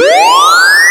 notify.wav